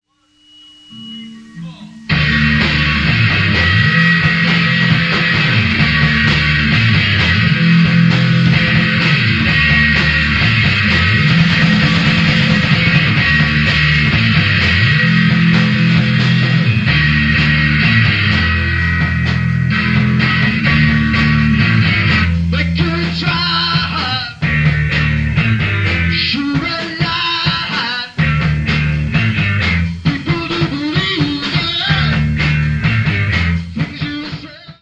Heavy Metal Rock.